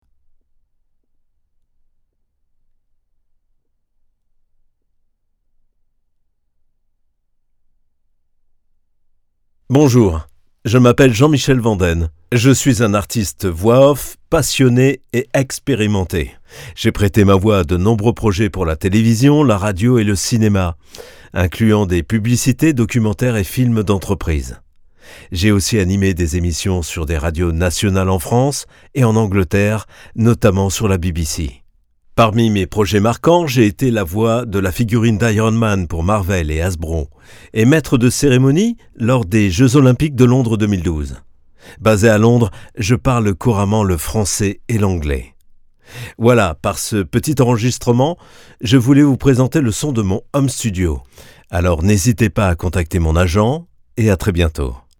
Male
Assured, Character, Confident, Conversational, Cool, Corporate, Deep, Engaging, Friendly, Gravitas, Natural, Reassuring, Sarcastic, Smooth, Soft, Versatile, Warm
Commercial Showreel FRENCH_01.mp3
Microphone: Neumann TLM103